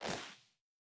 Jump Step Snow A.wav